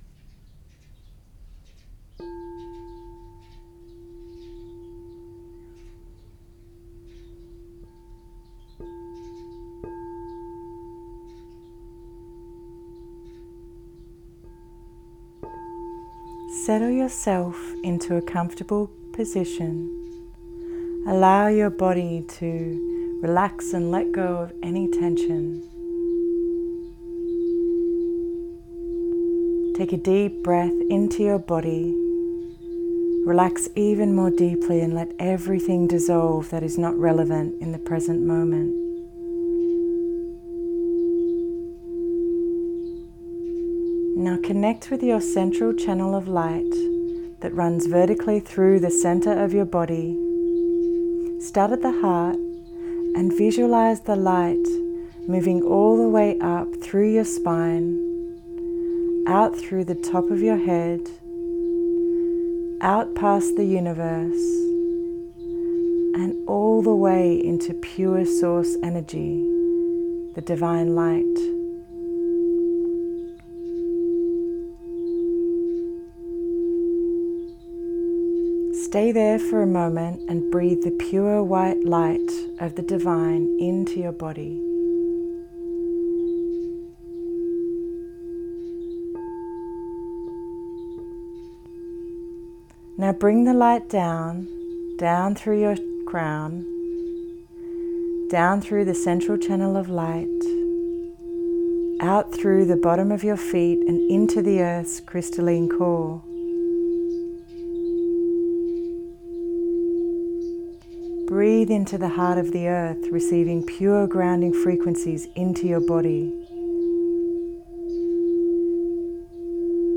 Download your Free Gift : Download meditation ~ WELCOME ~ I am so happy to share this gift from my heart.